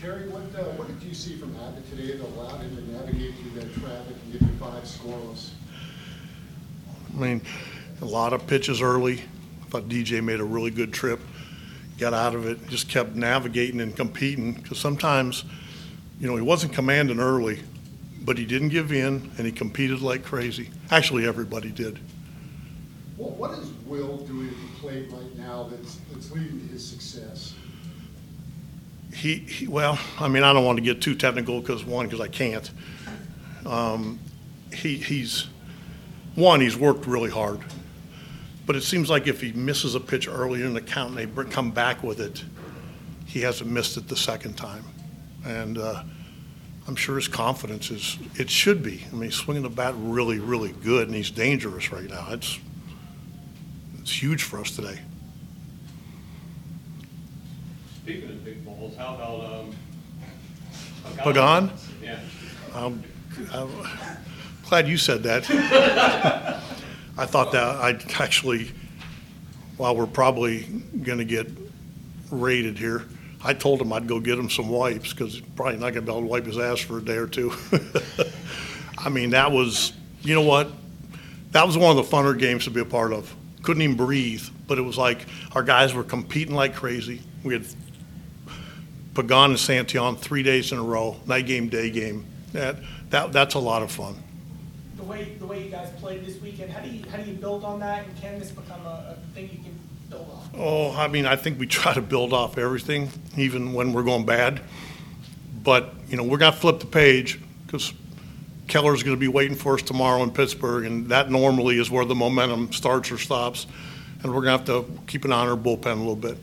Terry Francona Reds manager Postgame 5 18, 2025 Reds sweep Guardians with 3-1 win